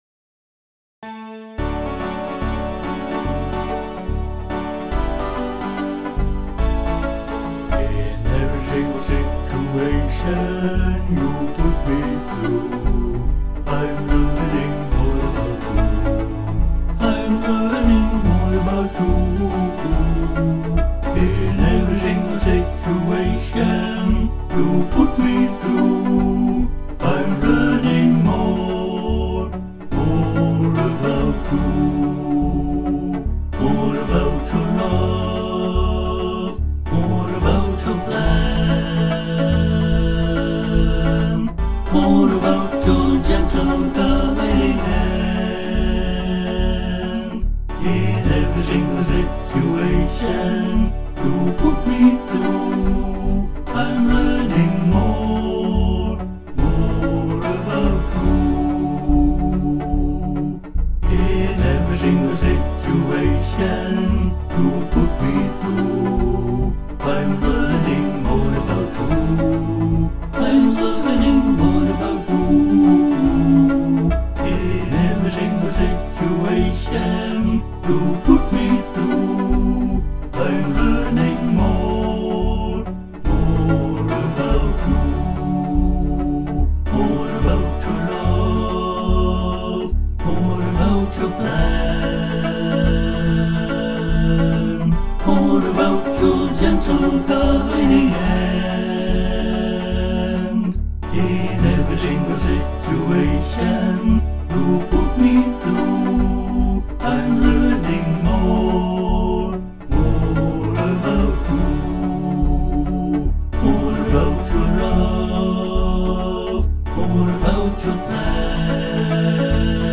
Joyfully, in a country style